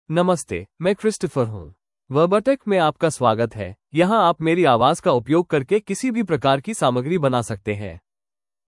Christopher — Male Hindi (India) AI Voice | TTS, Voice Cloning & Video | Verbatik AI
ChristopherMale Hindi AI voice
Voice sample
Male
Christopher delivers clear pronunciation with authentic India Hindi intonation, making your content sound professionally produced.